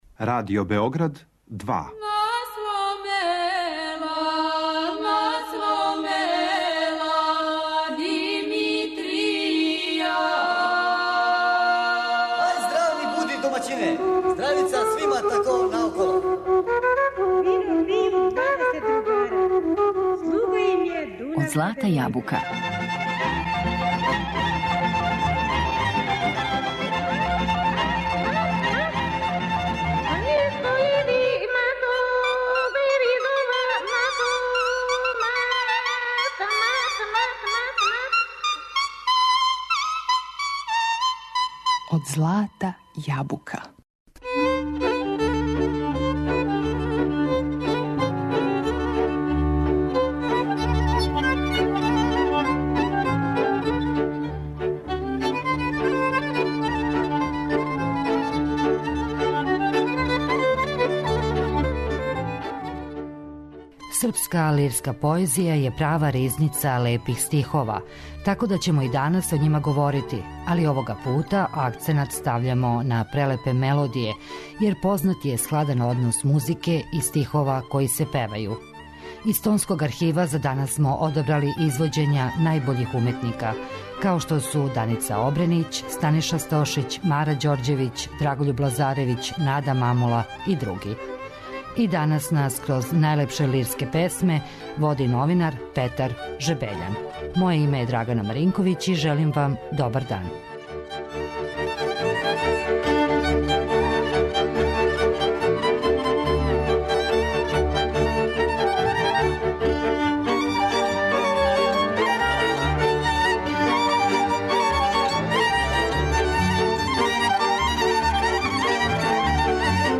Српска лирска поезија је права ризница лепих стихова, тако да ћемо и данас о њима говорити, али овога пута акценат стављамо на прелепе мелодије, јер је познат складан однос музике и стихова који се певају.
Из Тонског архива данас смо одабрали извoђења Данице Обренић